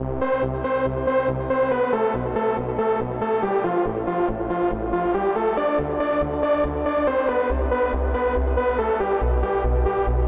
这个版本是另一台钢琴的声音。
声道立体声